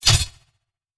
Whoosh Blade 004.wav